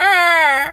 bird_vulture_croak_01.wav